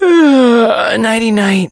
sandy_die_vo_04.ogg